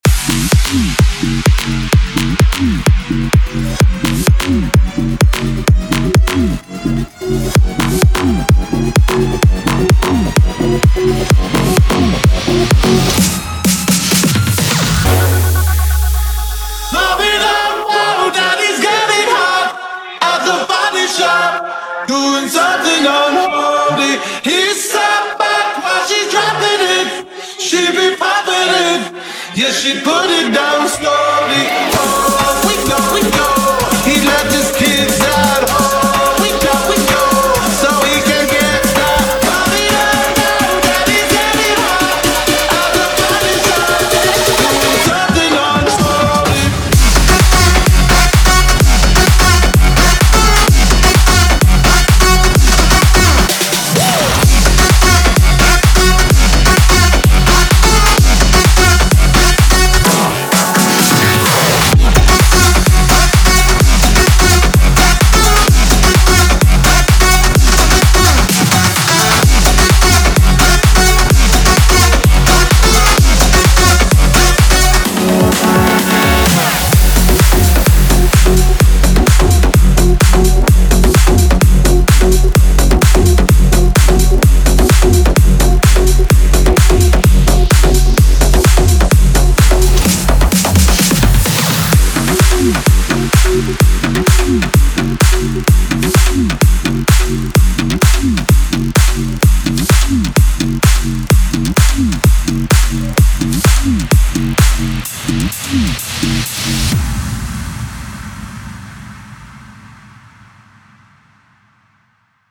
EDM Remix | Dance Remix Song
Category: Latest Dj Remix Song